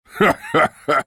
vs_fVhailor_haha.wav